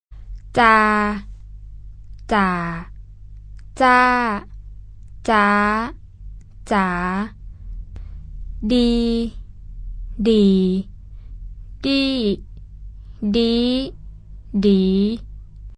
MIDLOWFALLINGHIGHRISING
tone_practice.mp3